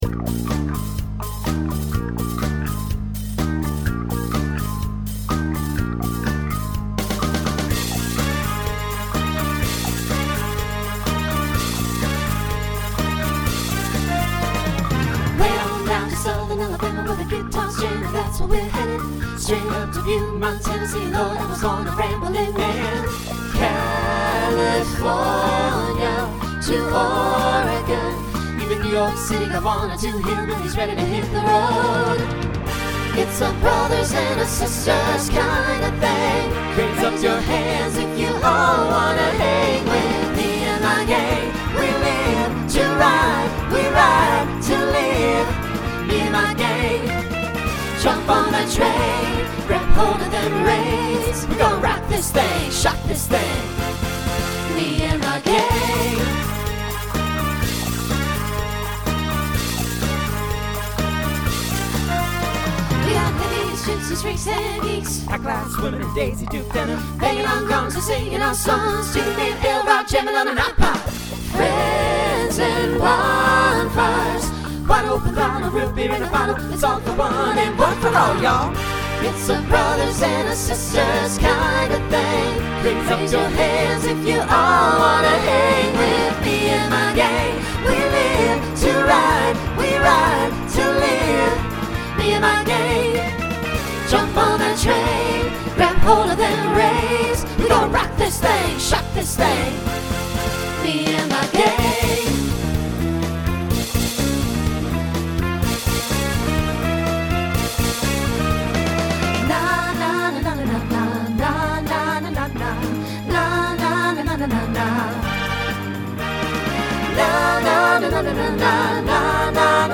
New SSA voicing for 2025.